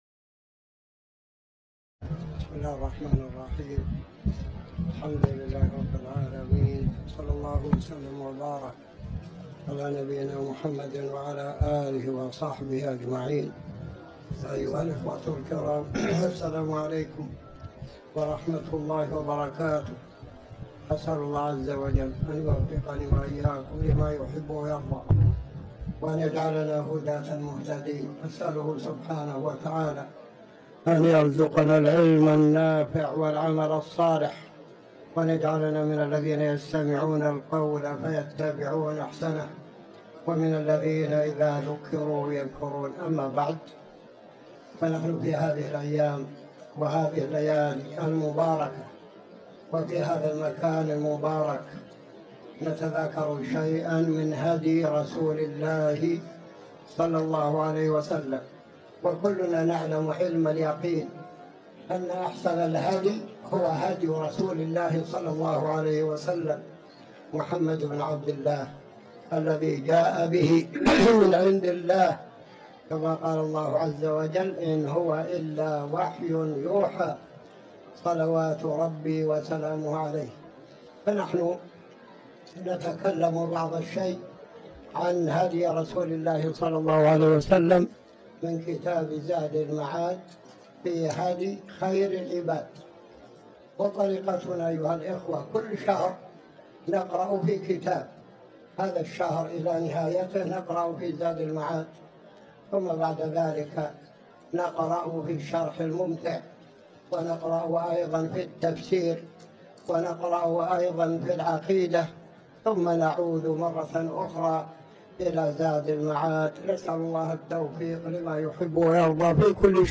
تاريخ النشر ٢١ جمادى الأولى ١٤٤٠ هـ المكان: المسجد الحرام الشيخ